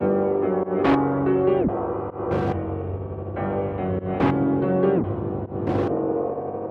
钢琴旋律 143 bpm g
Tag: 143 bpm Trap Loops Piano Loops 1.13 MB wav Key : G